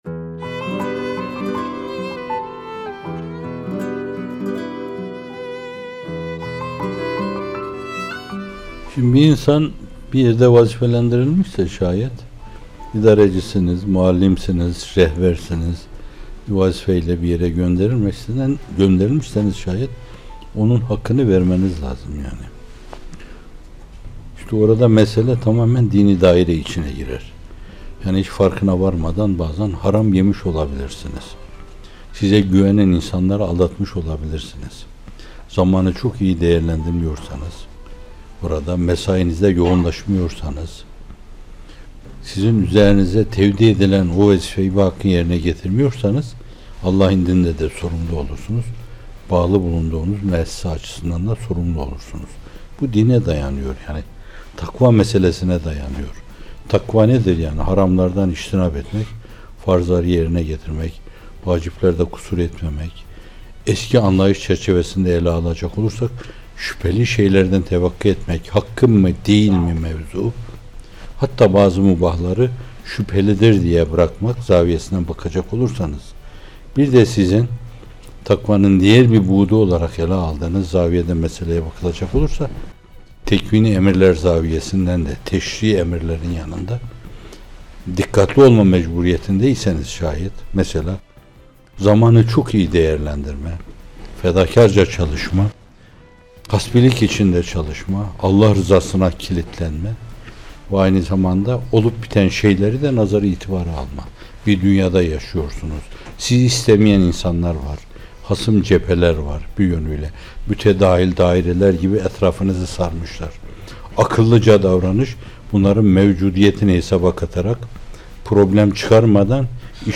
Bir Nefes (78) – Zaman Tanzimi ve Takva - Fethullah Gülen Hocaefendi'nin Sohbetleri